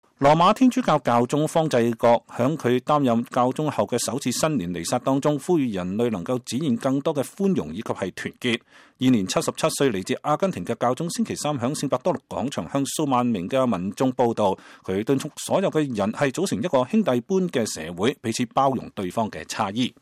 羅馬天主教教宗方濟各在他擔任教宗後的首次新年彌撒中，呼籲人類能夠展現更多的寬容和團結。現年77歲，來自阿根廷的教宗星期三在聖伯多祿廣場向數萬民眾佈道。